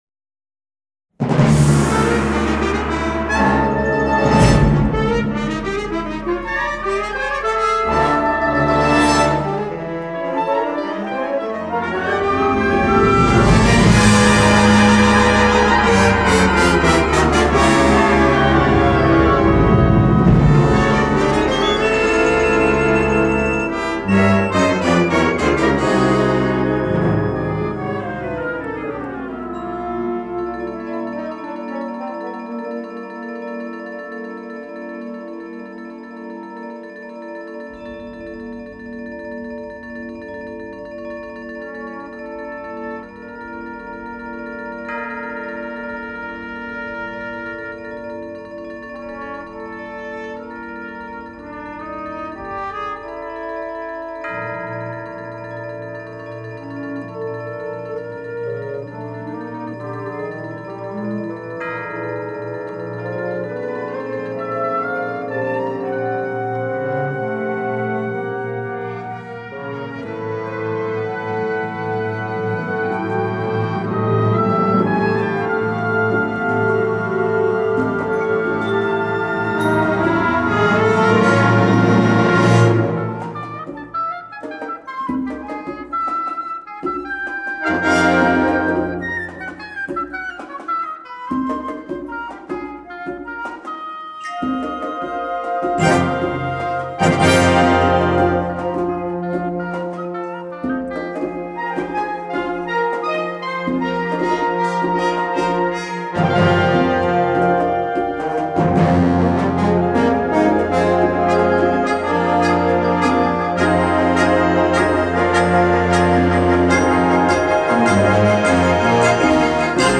Partitions pour orchestre d'harmonie et - fanfare.
• View File Orchestre d'Harmonie